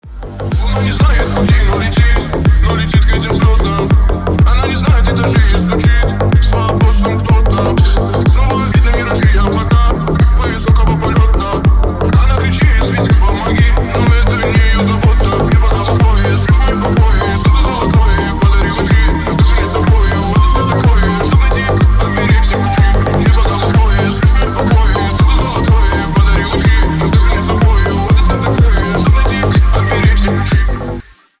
Ремикс
клубные # ритмичные